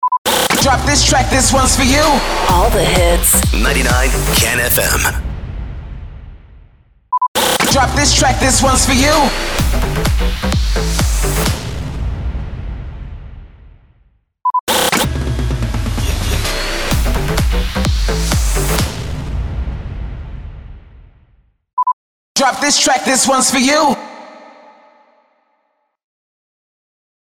301 – SWEEPER – DROP THIS TRACK
301-SWEEPER-DROP-THIS-TRACK.mp3